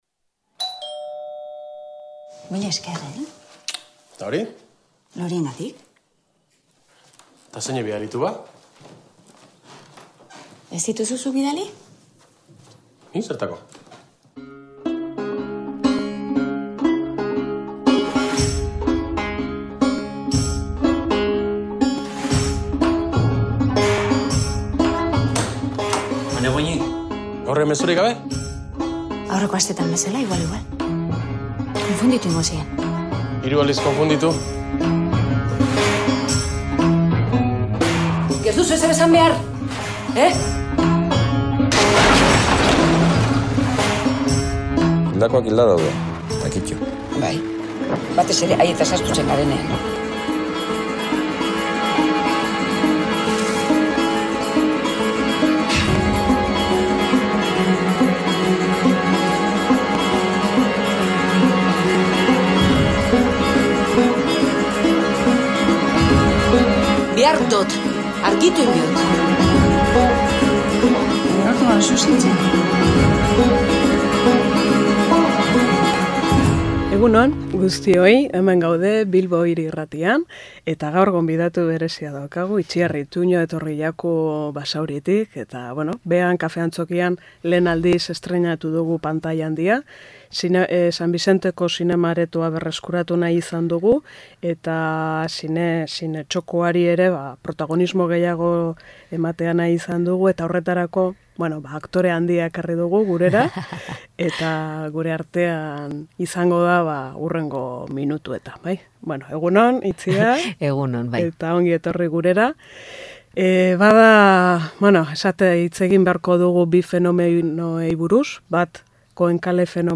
Aurreko astean Loreak (2014) filma proiektatu zen Kafe Antzokian eta, hori dela eta, Itziar Ituño aktorearen bisita izan genuen gurean. Hemen dituzue berari lapurtutako hitzak, barreak eta ametsak.